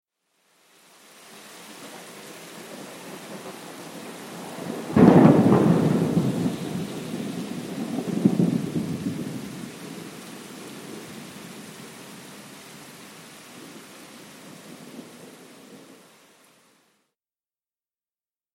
دانلود آهنگ رعد و برق 3 از افکت صوتی طبیعت و محیط
دانلود صدای رعد و برق 3 از ساعد نیوز با لینک مستقیم و کیفیت بالا
جلوه های صوتی